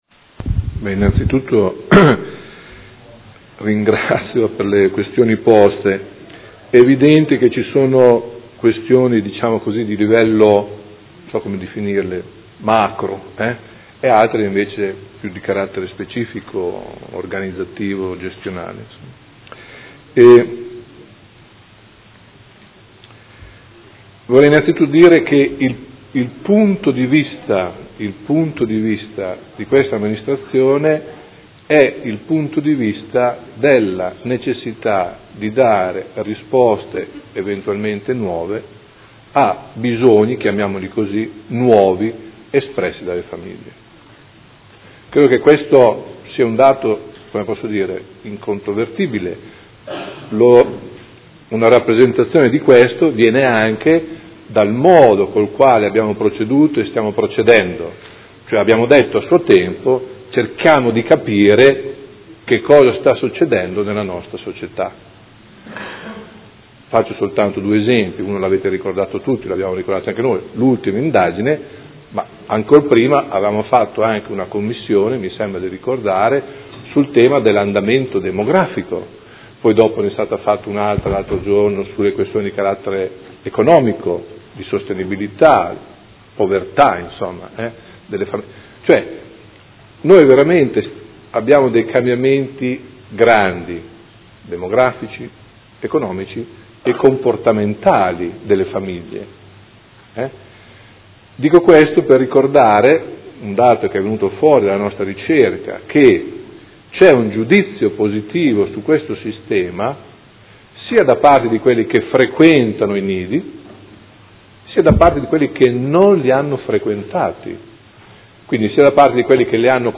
Gianpietro Cavazza — Sito Audio Consiglio Comunale
Dibattito. Interrogazione del Consigliere Rocco (FaS-S.I.) avente per oggetto: Bando comunale servizio nidi e Interrogazione del Gruppo Consiliare Per Me Modena avente per oggetto: Qual è la situazione dei nidi a Modena?